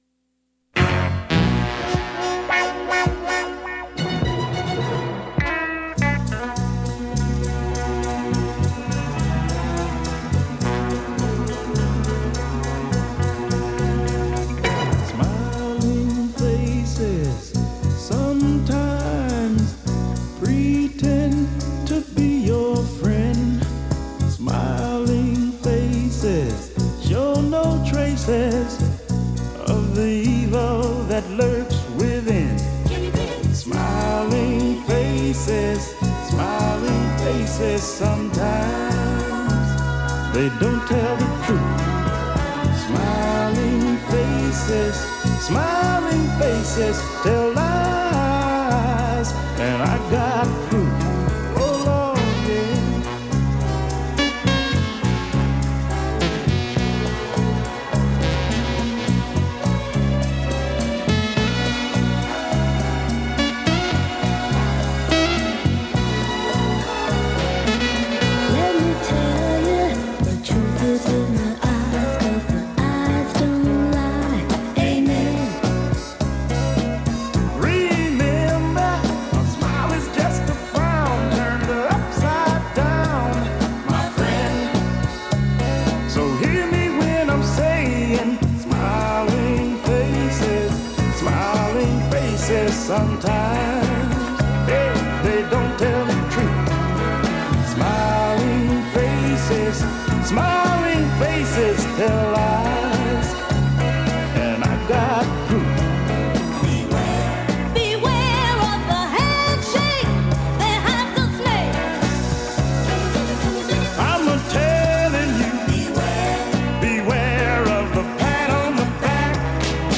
Music, talk, and schtick, just like any variety show. One difference, though, is the Democratic Socialists also confront power.